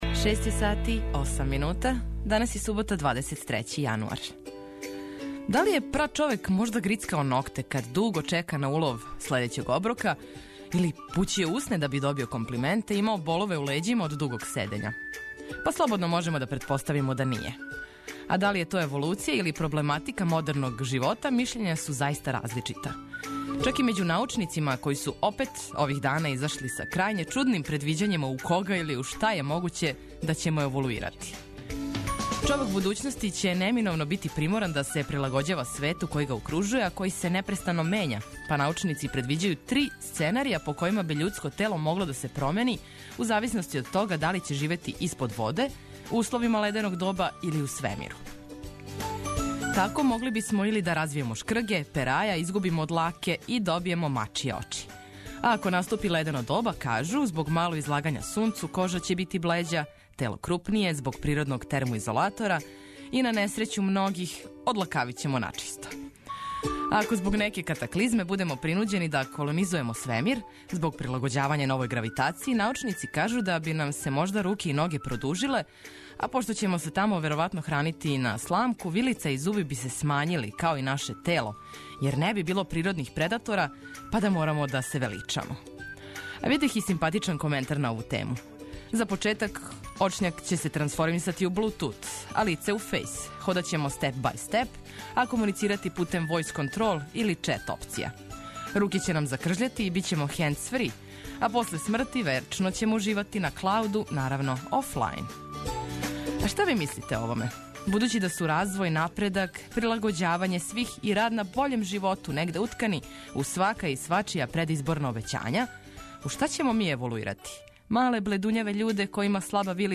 Суботње јутро започињемо лежерно, лагано, ведро и опуштено – на вама је да се препустите доброј музици, а ми ћемо вас уз обиље корисних, битних и позитивних информација поставити на „десну ногу“!